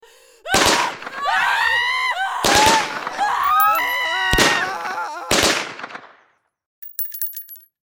На этой странице представлены аудиозаписи, имитирующие звуки выстрелов.